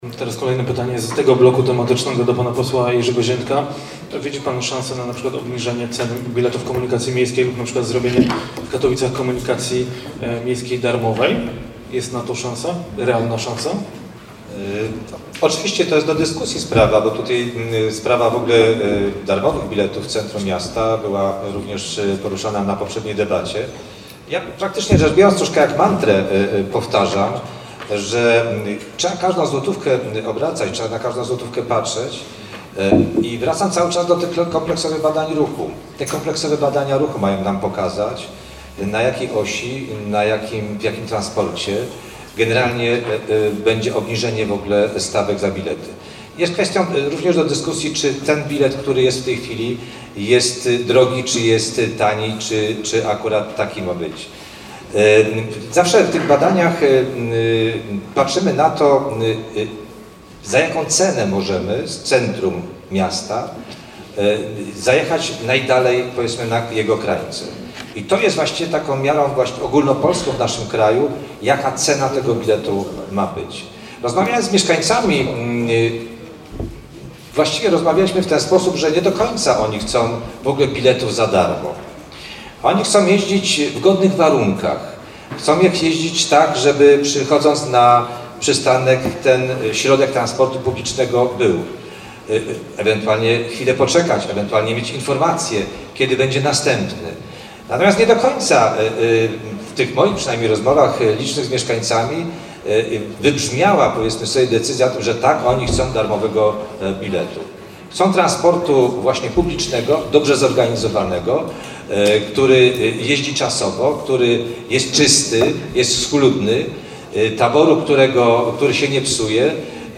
Debata kandydatów: Komunikacja w mieście.
We wtorek w BWA odbyła się debata kandydatów na prezydenta miasta Katowice.